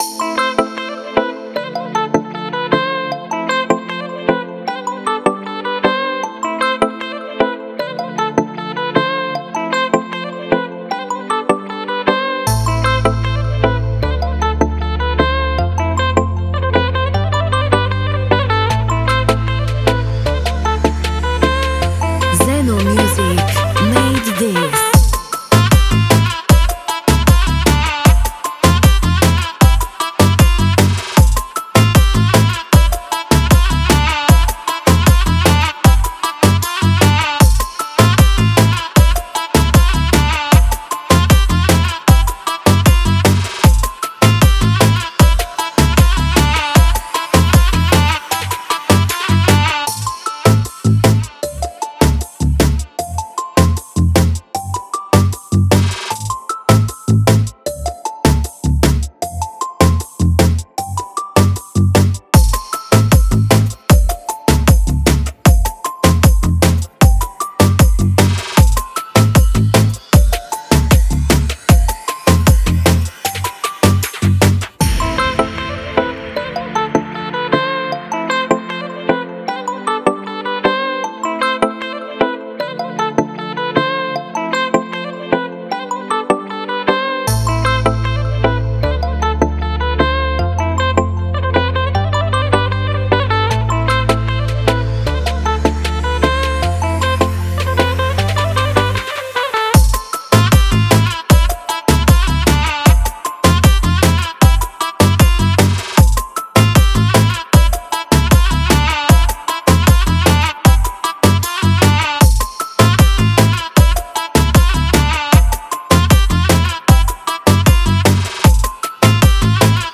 Oriental Beat Balkan Dancehall Instrumental